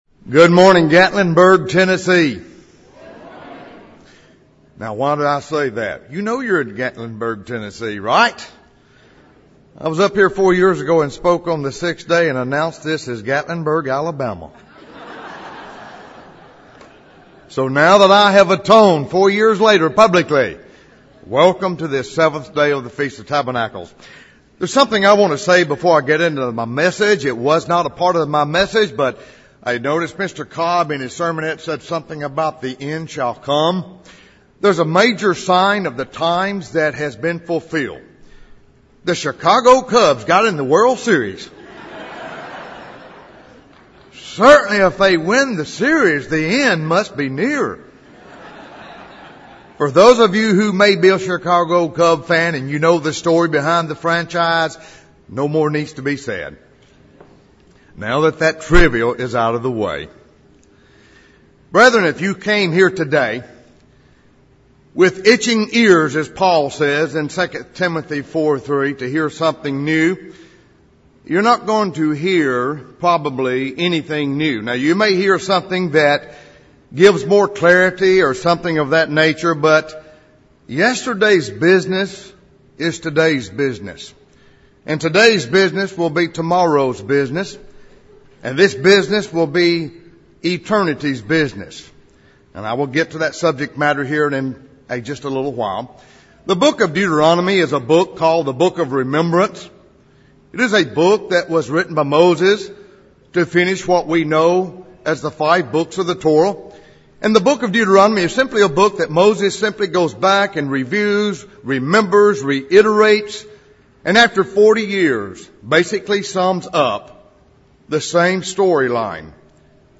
This sermon was given at the Gatlinburg, Tennessee 2016 Feast site.